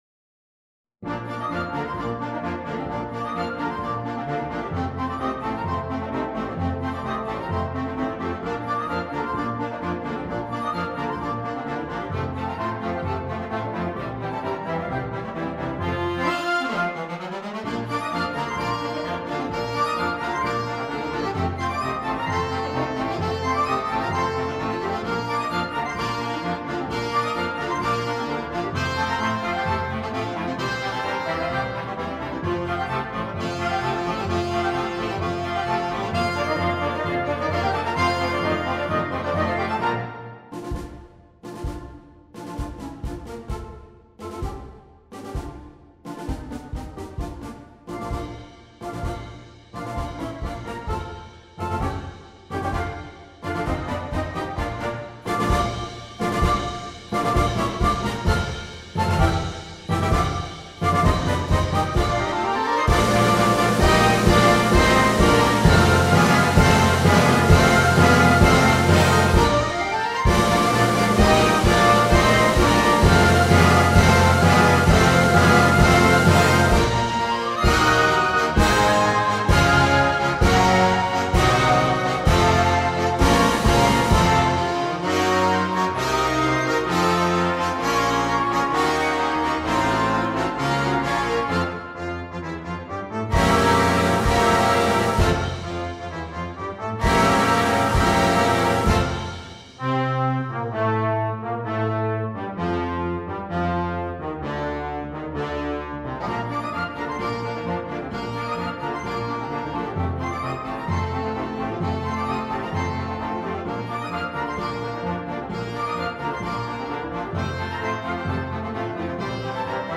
per coro ad libitum e banda